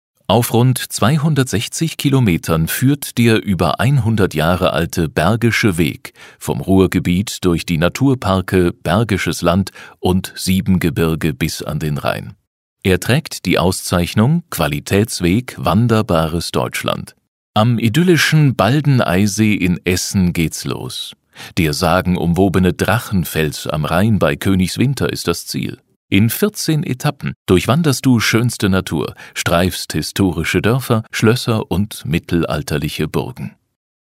audioguide-bergischer-weg.mp3